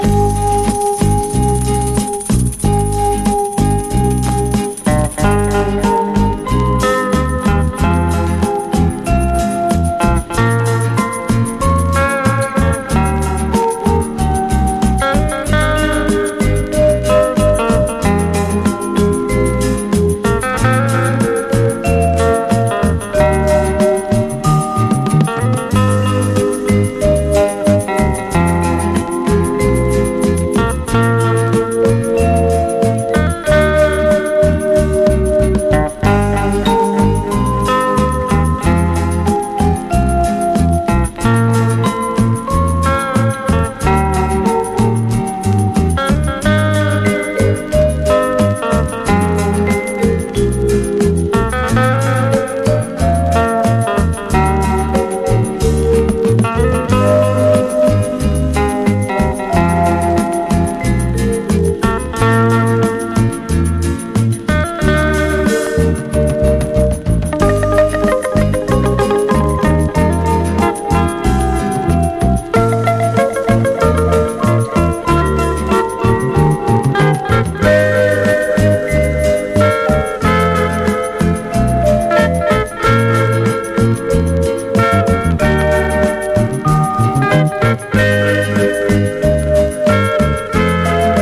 ペルー産サイケデリック・クンビア！